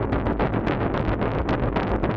Index of /musicradar/rhythmic-inspiration-samples/110bpm